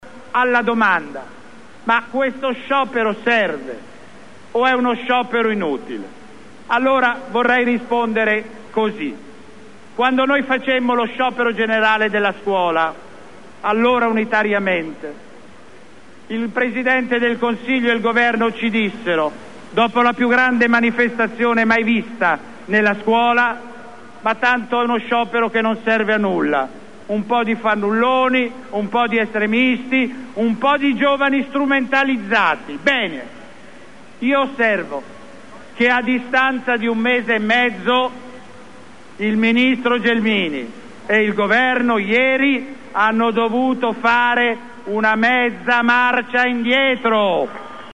Sciopero generale di otto ore indetto dalla Cgil, in duecentomila per la manifestazione regionale a Bologna.
“Quando facemmo lo sciopero della scuola, allora unitariamente”, ha detto ancora Epifani dal palco, “il Presidente del Consiglio e il governo ci dissero che non serviva a niente.
“La Lotta paga”, ascolta Epifani sulla Scuola: